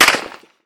light_crack_02.ogg